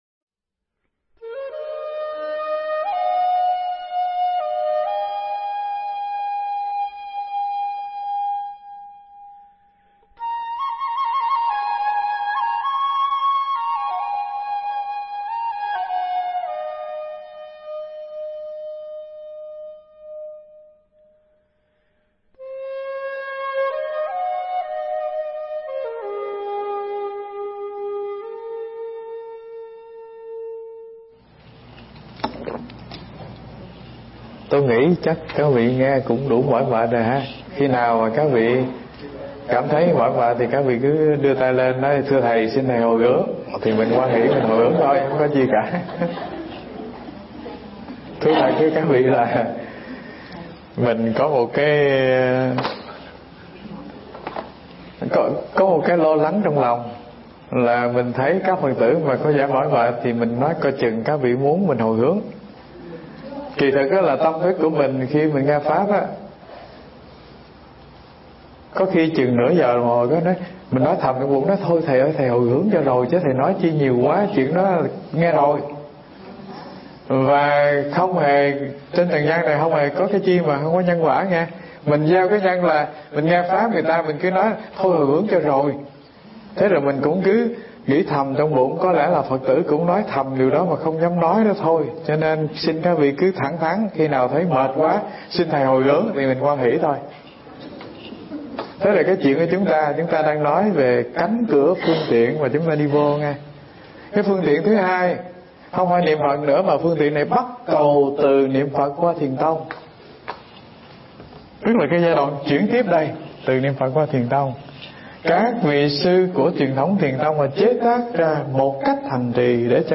Nghe Mp3 thuyết pháp Tùy Thuận Pháp Phần 3b